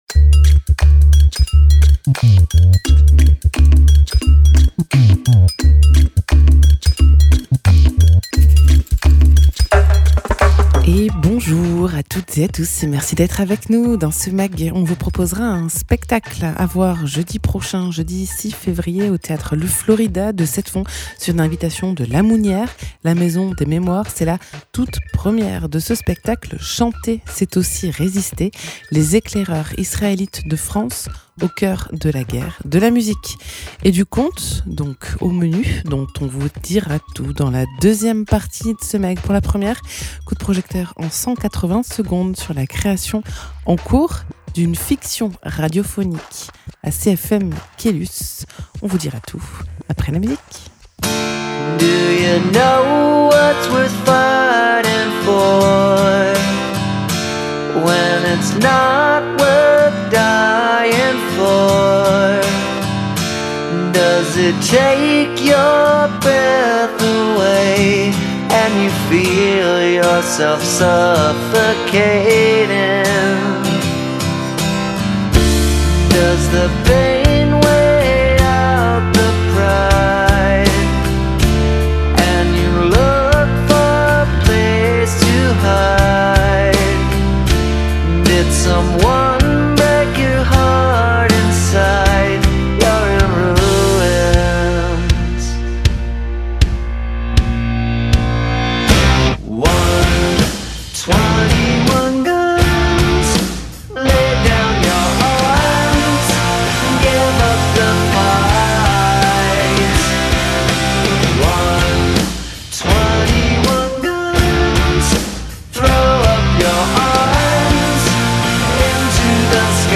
chanteuse